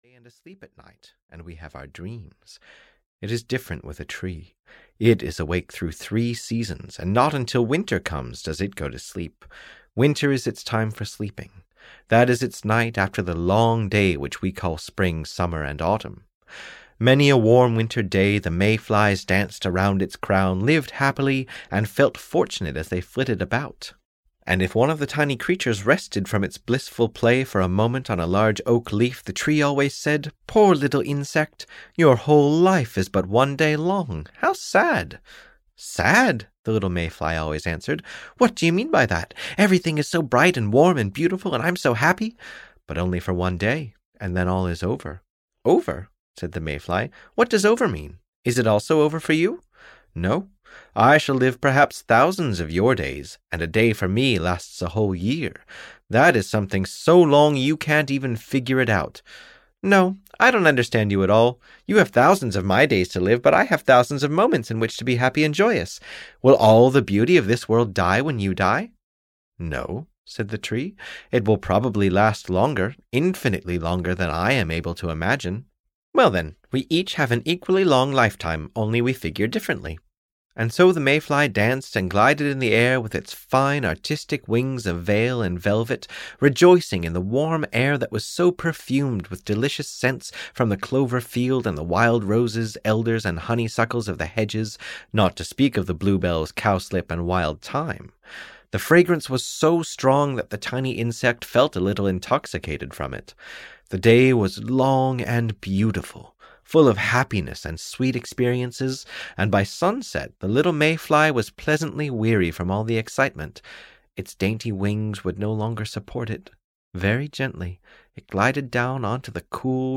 Audio knihaThe Old Oak Tree's Last Dream (EN)
Ukázka z knihy